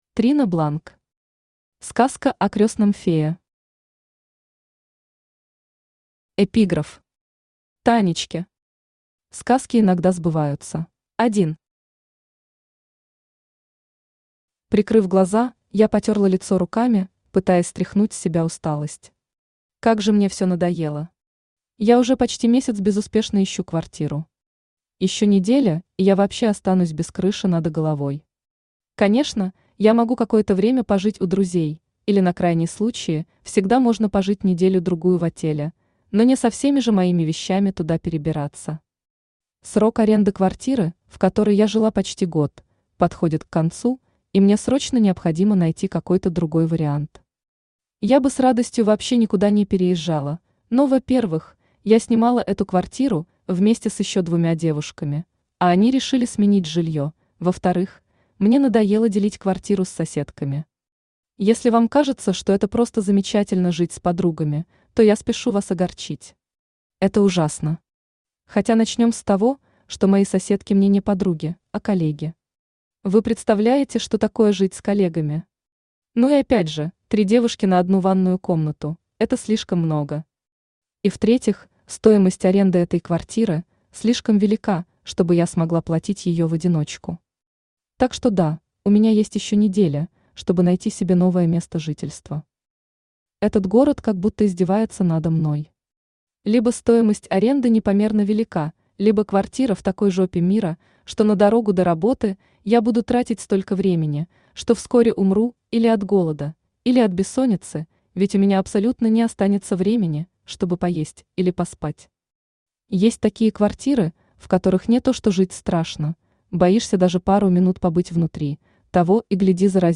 Аудиокнига Сказка о крёстном фее | Библиотека аудиокниг
Aудиокнига Сказка о крёстном фее Автор Трина Бланк Читает аудиокнигу Авточтец ЛитРес.